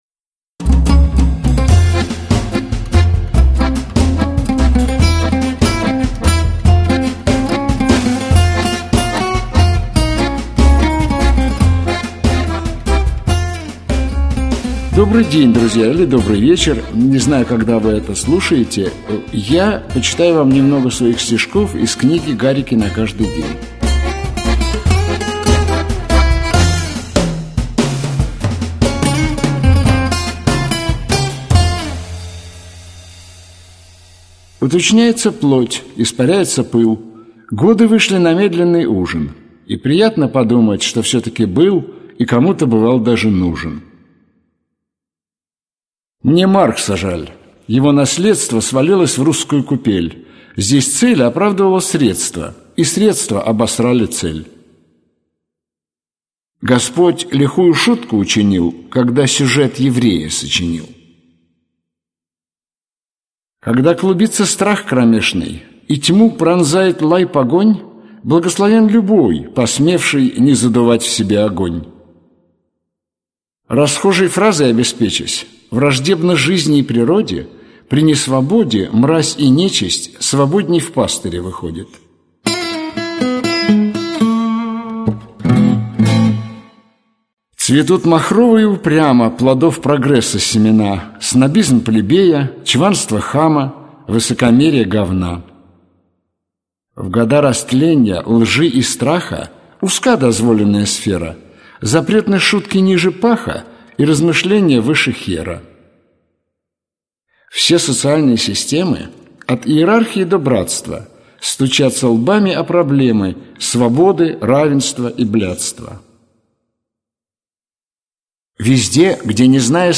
Аудиокнига Гарики на каждый день | Библиотека аудиокниг
Aудиокнига Гарики на каждый день Автор Игорь Губерман Читает аудиокнигу Игорь Губерман.